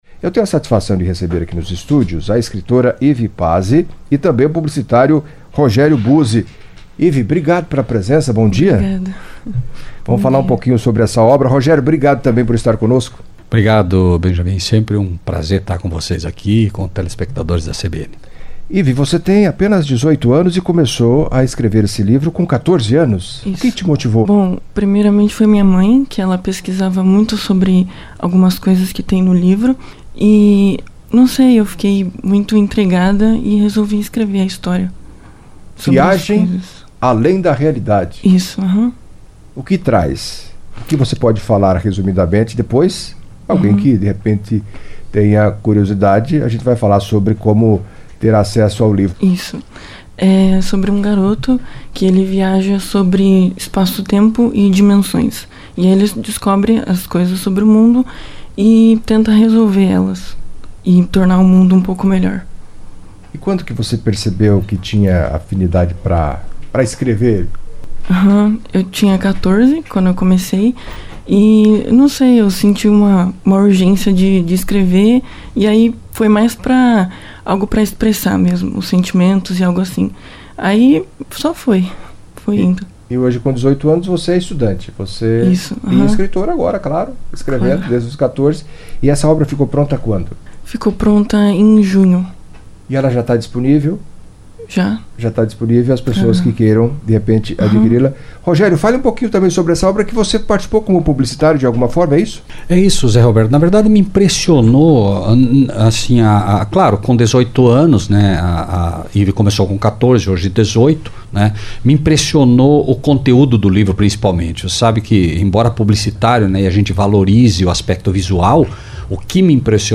Em entrevista à CBN Cascavel nesta quarta-feira (9)